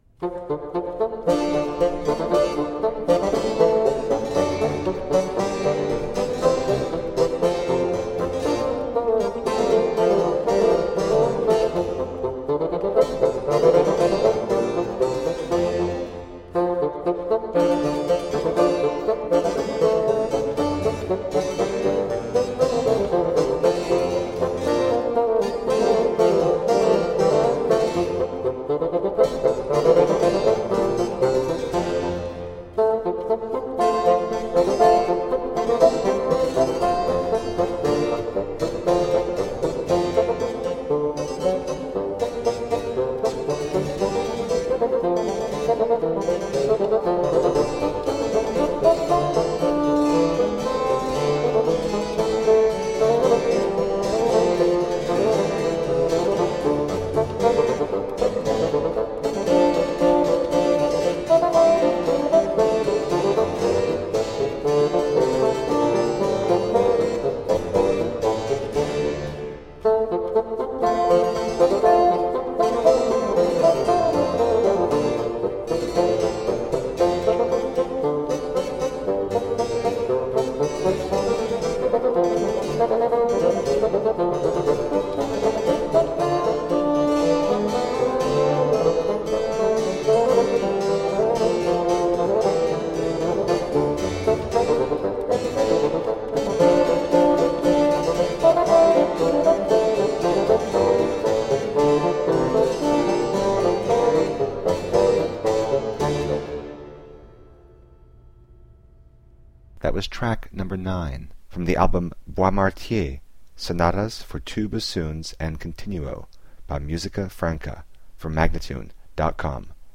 Classical, Chamber Music, Baroque, Instrumental, Bassoon
Harpsichord, Organ